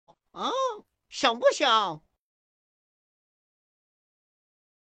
Cute, adorable and naughty cat sound effects free download